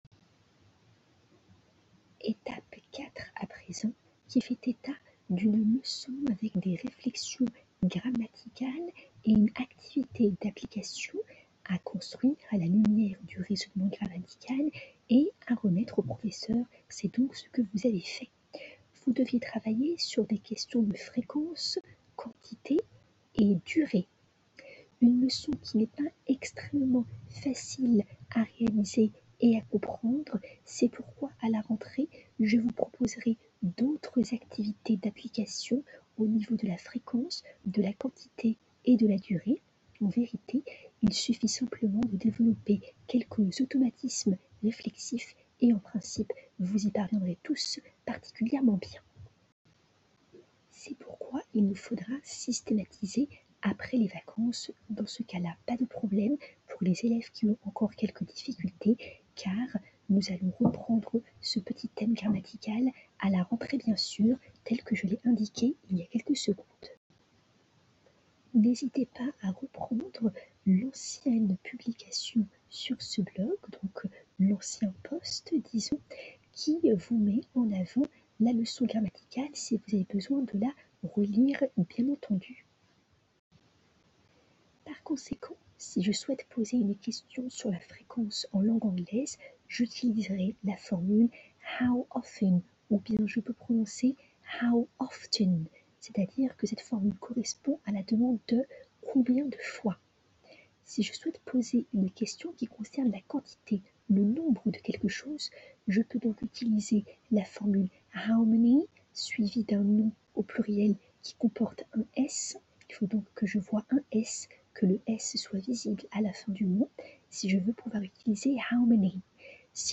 Je vous souhaite une très bonne écoute des pistes audio ci-dessous mettant en avant les explications orales du professeur relativement à la leçon à laquelle vous aviez à réfléchir, en classe inversée, pour le jeudi 02 avril 2020.
Audio 4 du professeur, d'une durée de 05:40: